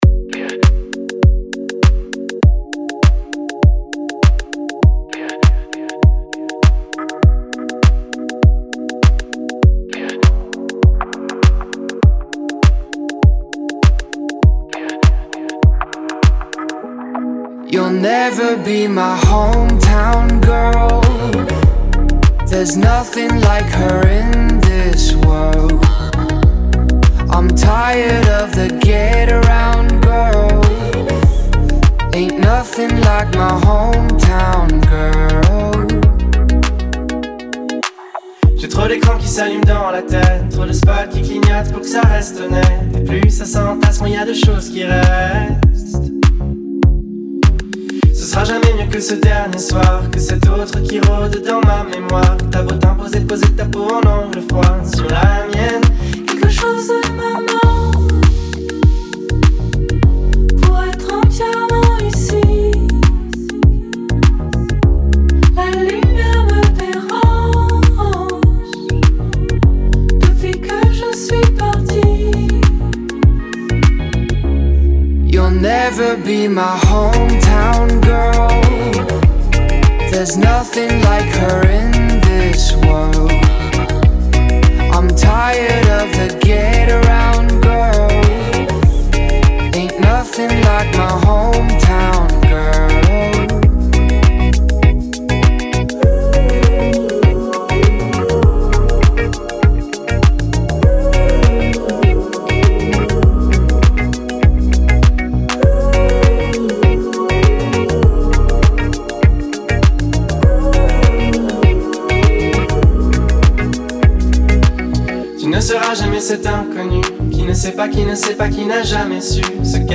это трек в жанре электронного попа